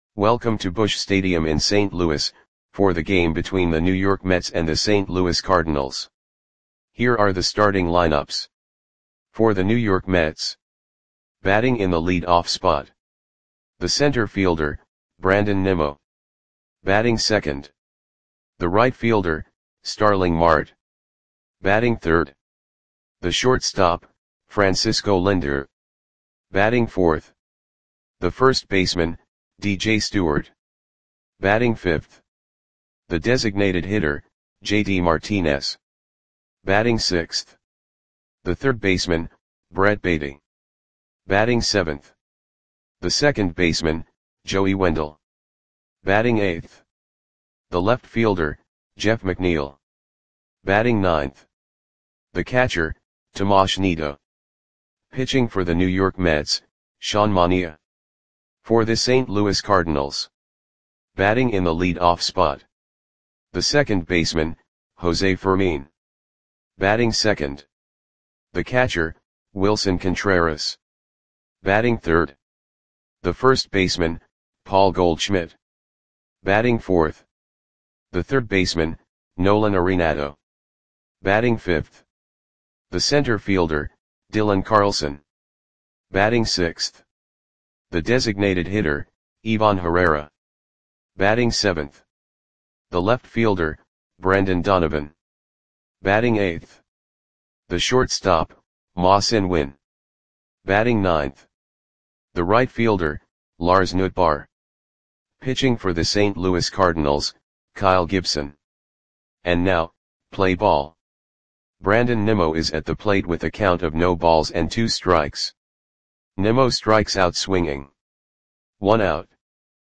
Audio Play-by-Play for St. Louis Cardinals on May 6, 2024
Click the button below to listen to the audio play-by-play.